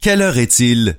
Click each word/phrase to hear the pronunciation.